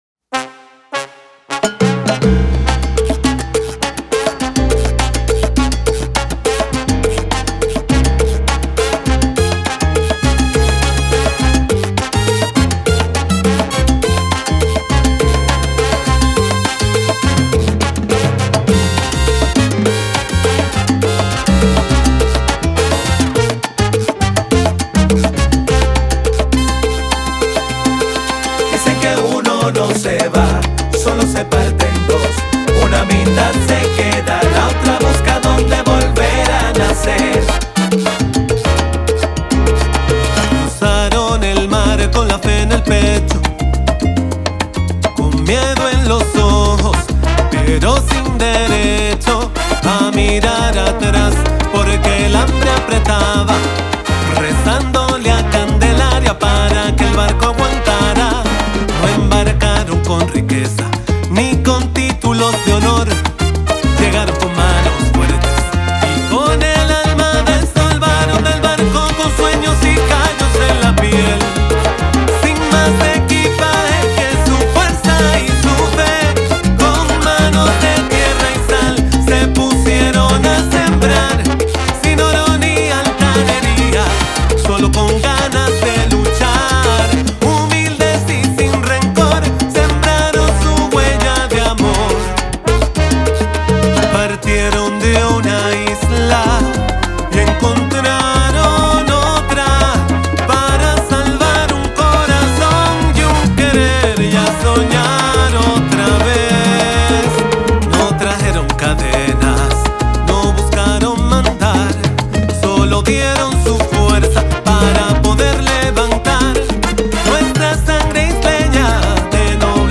una salsa profundamente emotiva